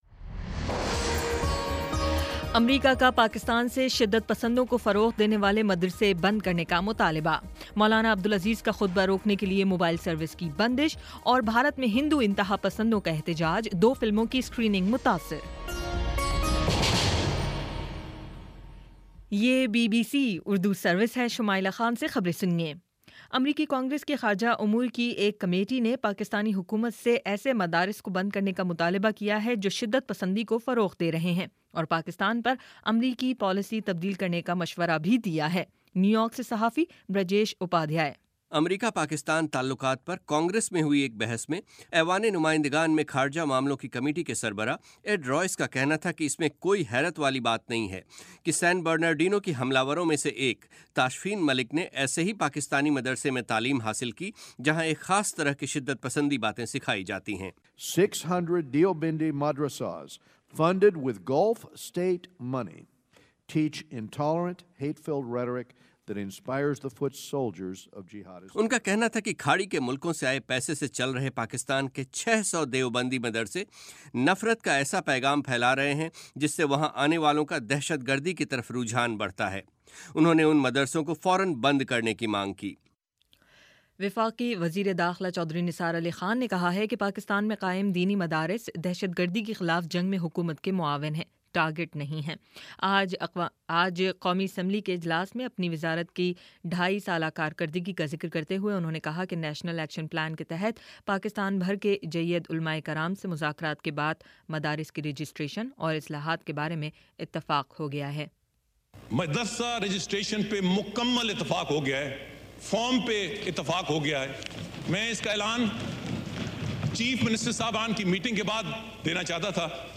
دسمبر 18 : شام چھ بجے کا نیوز بُلیٹن